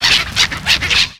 Cri de Vostourno dans Pokémon X et Y.